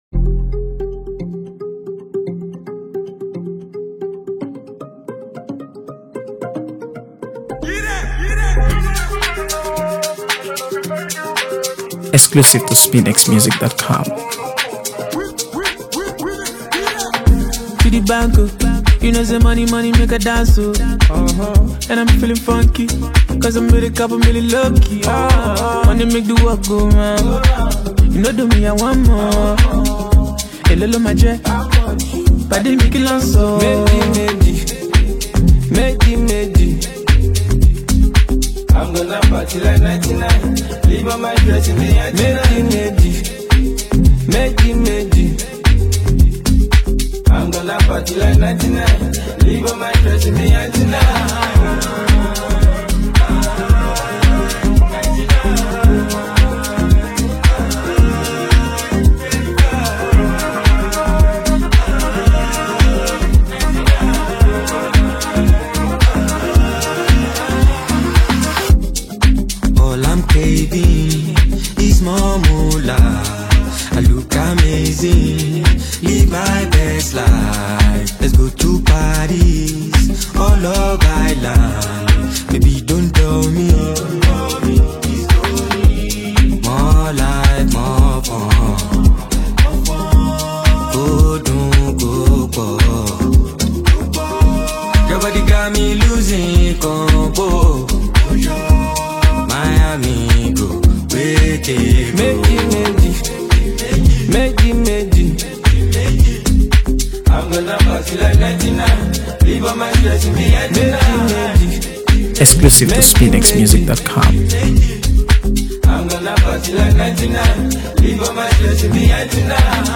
AfroBeats | AfroBeats songs
This record is bold, vibrant, and unforgettable.